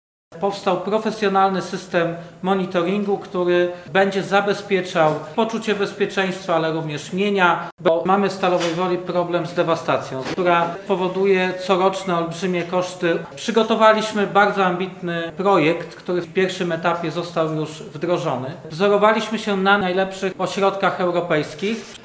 Kolejne kamery pojawiające się w Stalowej Woli to efekt tworzonego przez miasto systemu miejskiego monitoringu, który ma poprawiać bezpieczeństwo i zapobiegać wandalizmowi. Mówił o tym prezydent Stalowej Woli Lucjusz Nadbereżny.